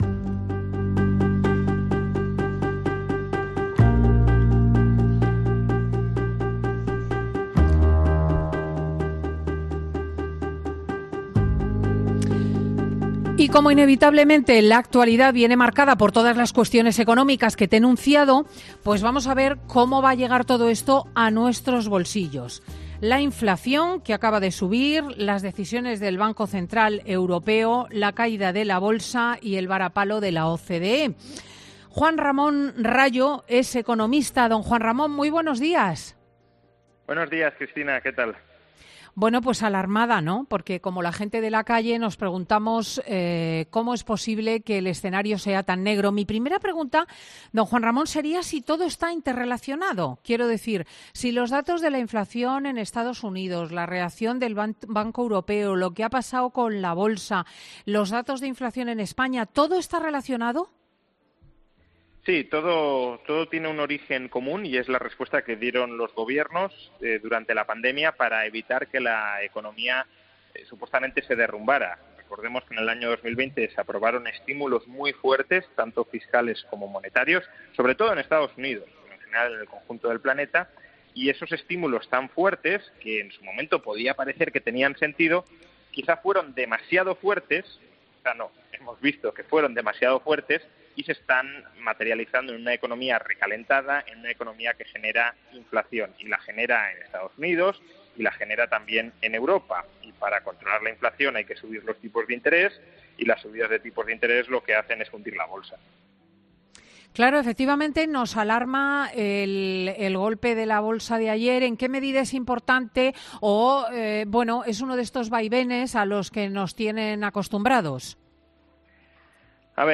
El reconocido economista dibuja en Fin de Semana el origen de la inflación y la crisis económica que se avecina para el próximo invierno